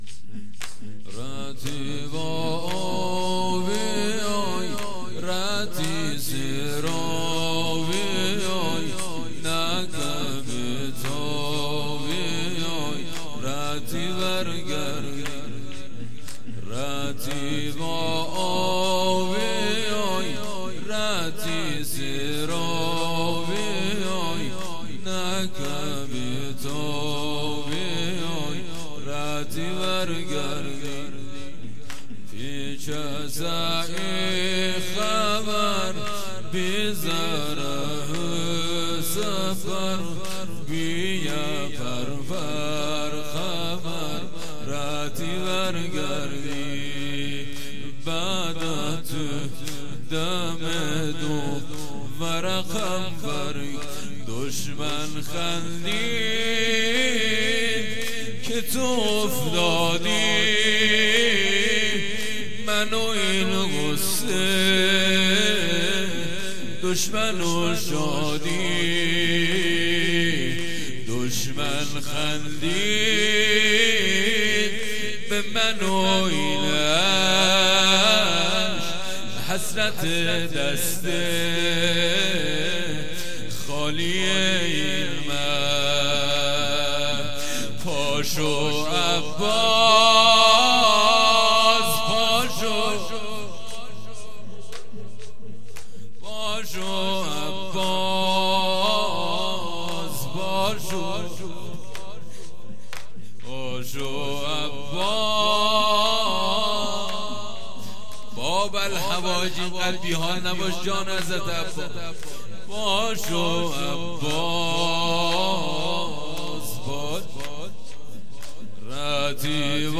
خیمه گاه - بوتراب علیـہ السلام - مداحی لری (رتی ورگردی)
وفات حضرت ام البنین (س)